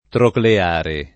trocleare [ trokle # re ]